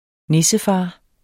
Udtale [ -ˌfɑː ]